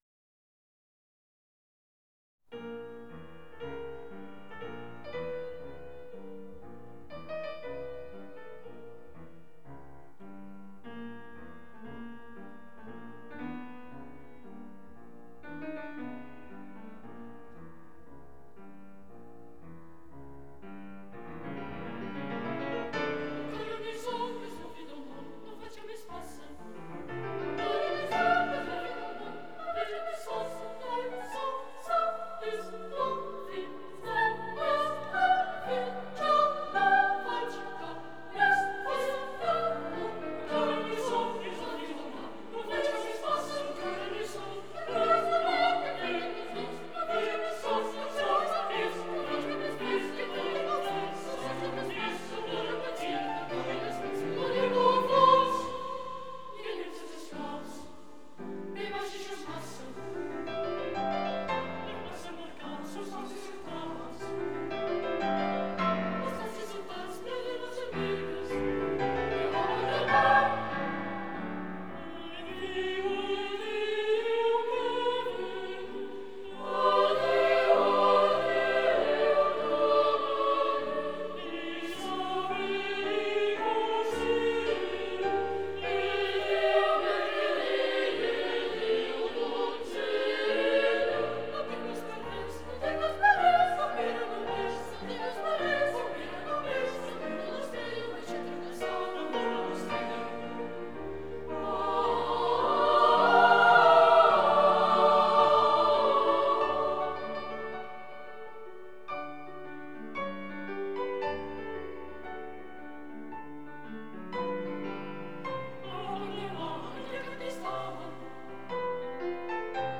Orgue
Католический хор мальчиков